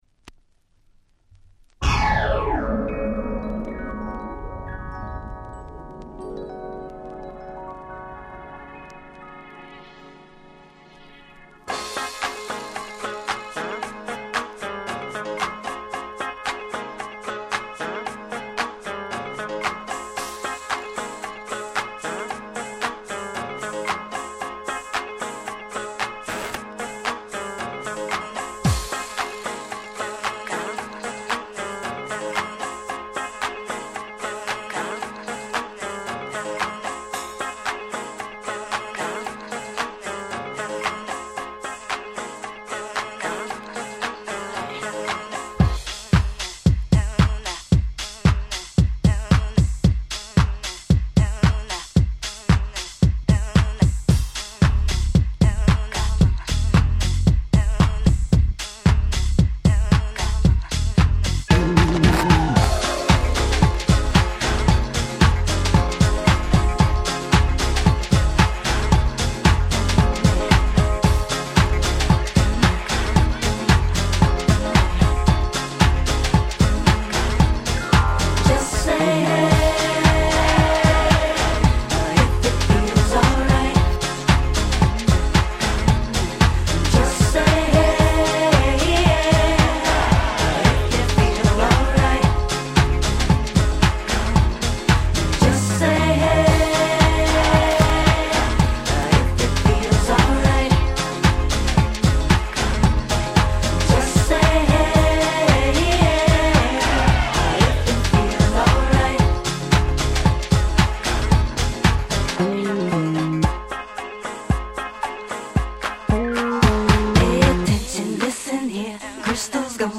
【Media】Vinyl 12'' Single
90's R&B Classic !!